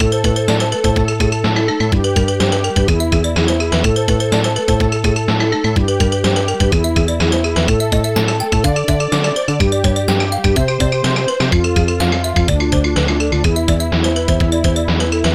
Protracker Module
2 channels
ST-04:hipbass1 ST-04:hipsnare1 ST-04:guitar1 ST-01:Licks ST-04:klink ST-04:weoow ST-04:synth ST-04:string snare